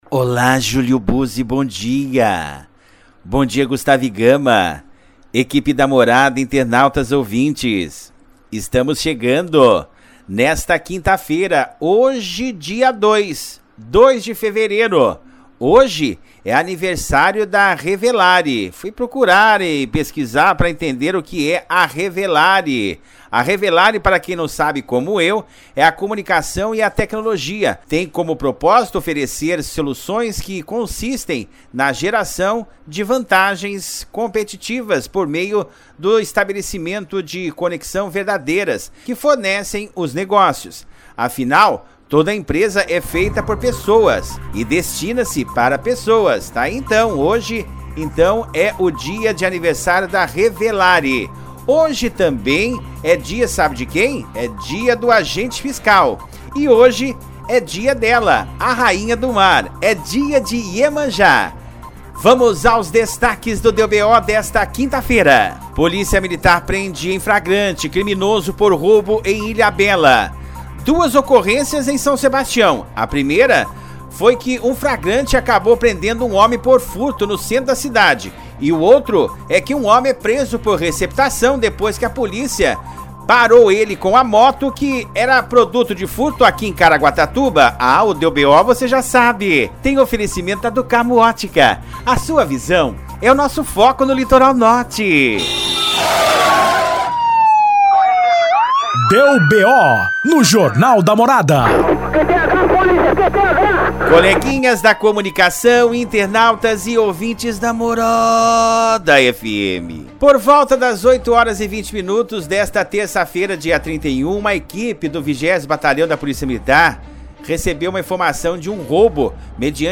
PODCAST: 02-02-23- Deu BO- segunda a sexta ao vivo na rádio Morada FM 95.5